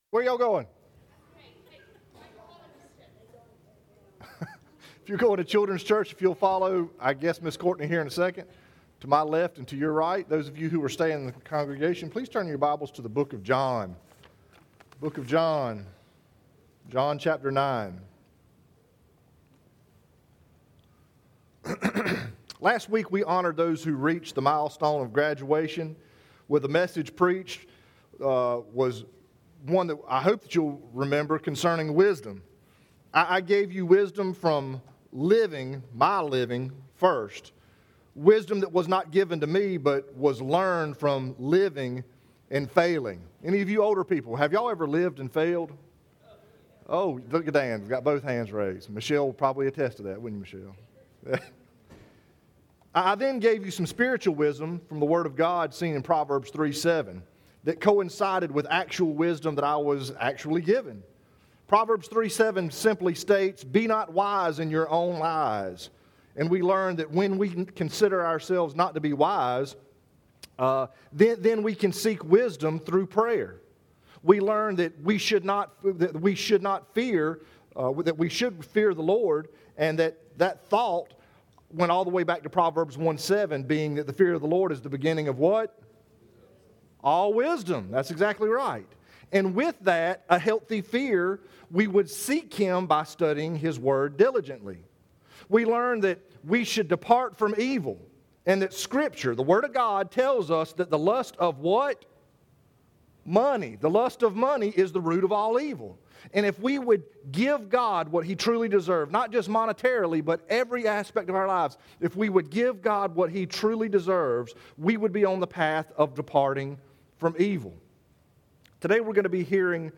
Chevis Oaks Baptist Church Sermons